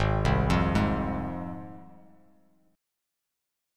Ab6 Chord
Listen to Ab6 strummed